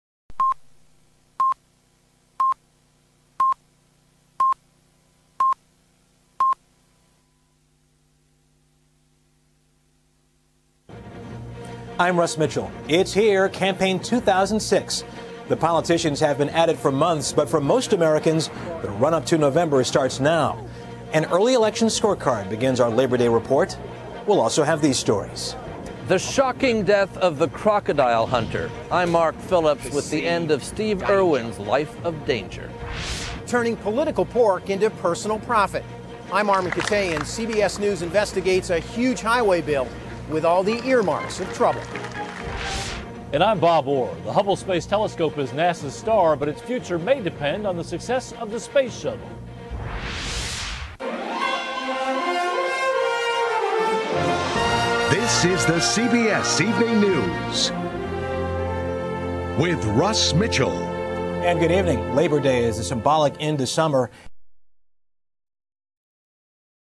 Générique